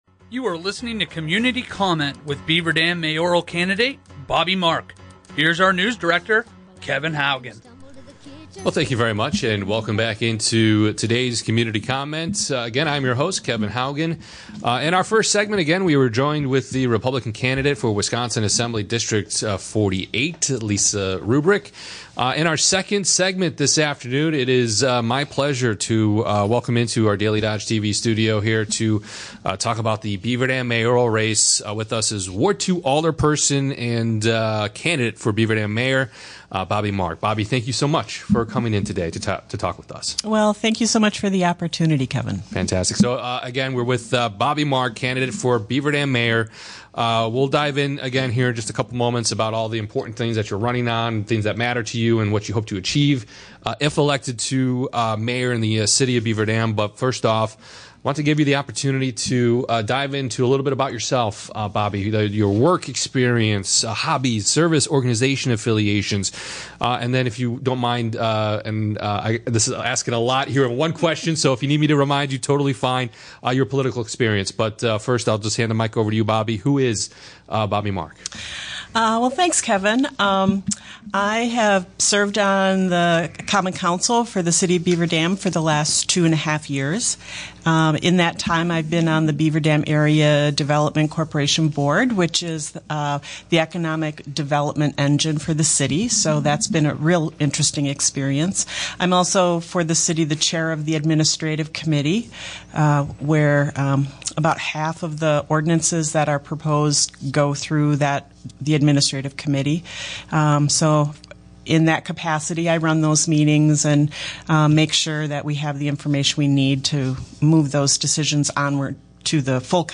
Everything you need to know about me in 25 minutes! Listen to my interview on WBEV's Community Comment.